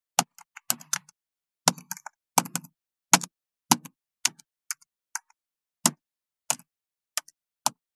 32.タイピング【無料効果音】
ASMRタイピング効果音
ASMR